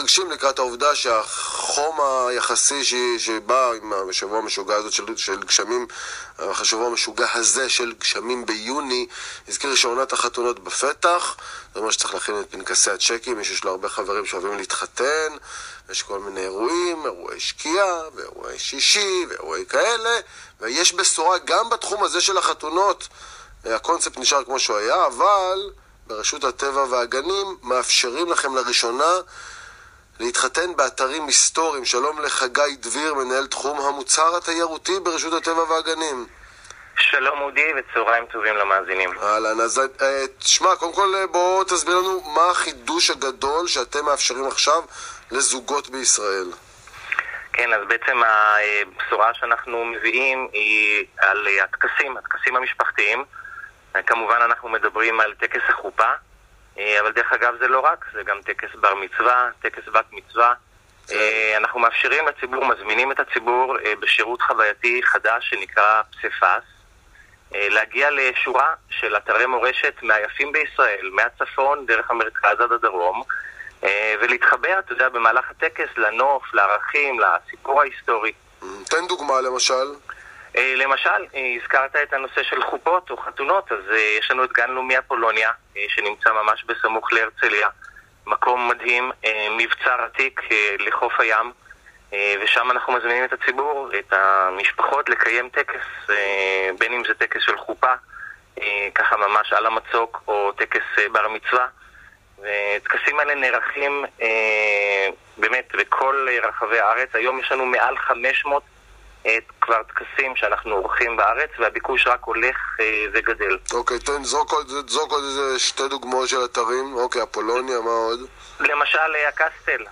ראיון רדיו